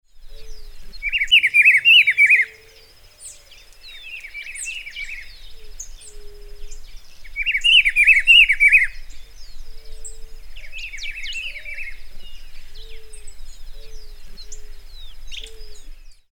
Rufous-browed Peppershrike (Cyclarhis gujanensis)
Life Stage: Adult
Location or protected area: Reserva Privada El Potrero de San Lorenzo, Gualeguaychú
Condition: Wild
Certainty: Observed, Recorded vocal